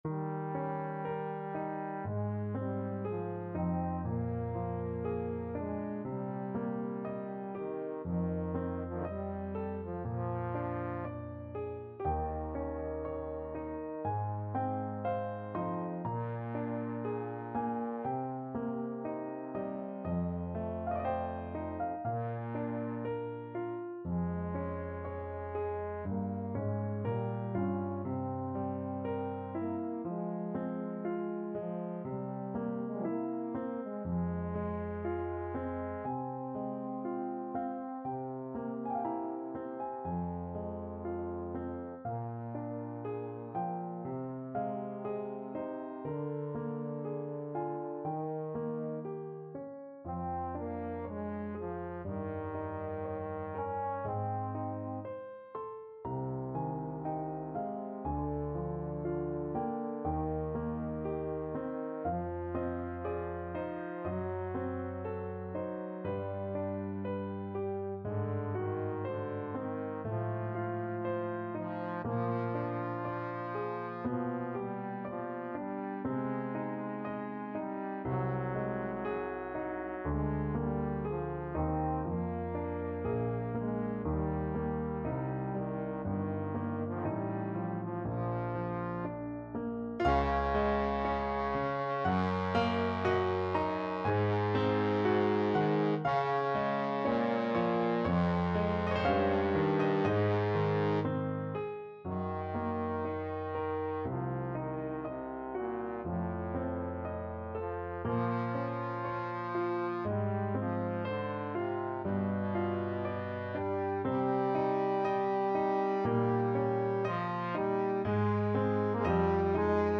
Trombone version
3/2 (View more 3/2 Music)
~ = 60 Largo
Classical (View more Classical Trombone Music)